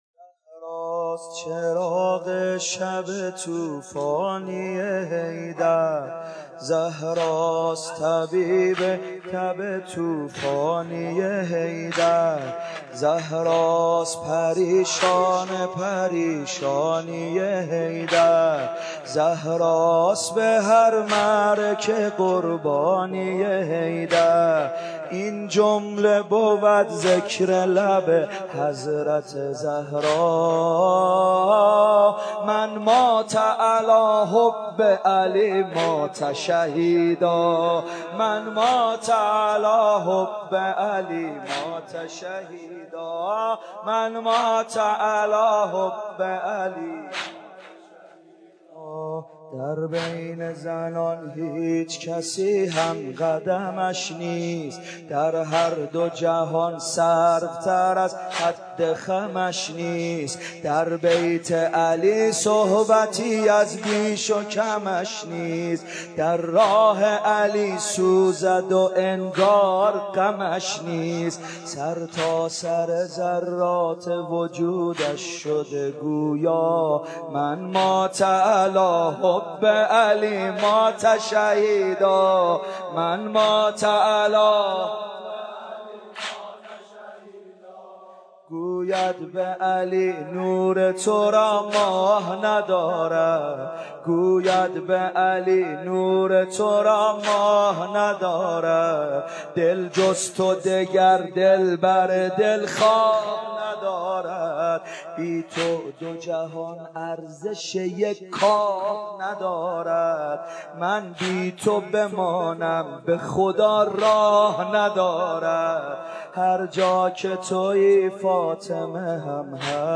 سینه زنی در شهادت بانوی بی نشان حضرت زهرا(س